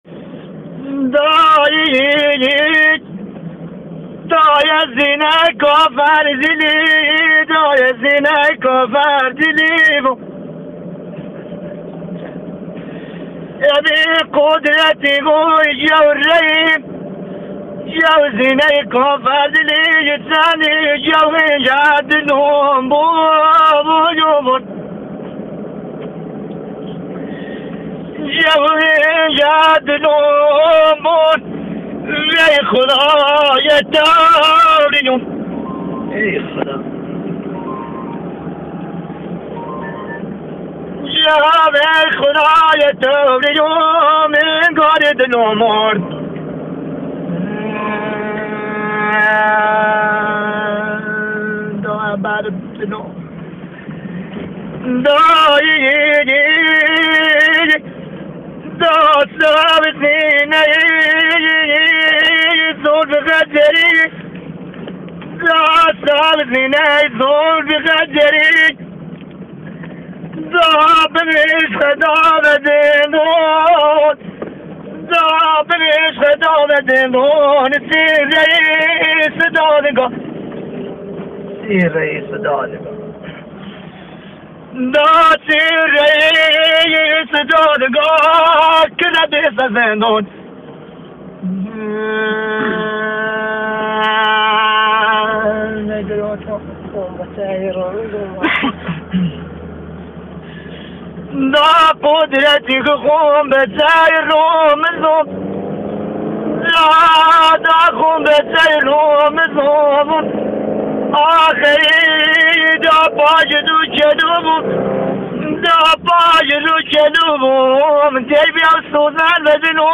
لری غمگین بهمیی